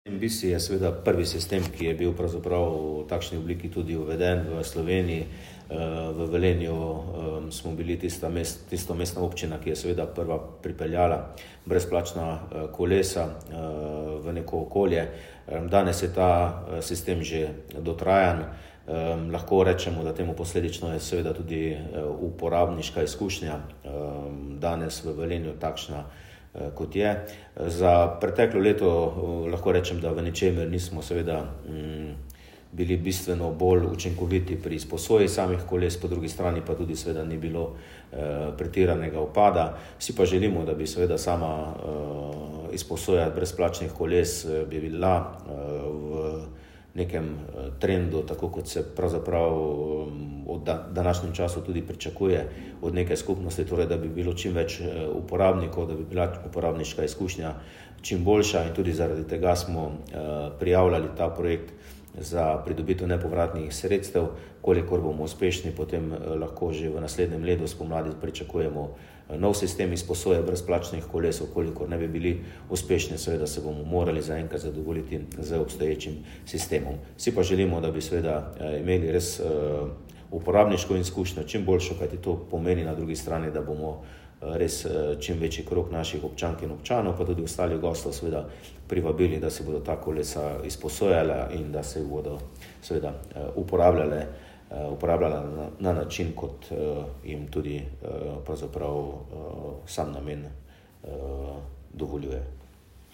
Župan MOV Peter Dermol:
Izjava zupana.mp3